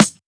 Snares
AGH_SNR.wav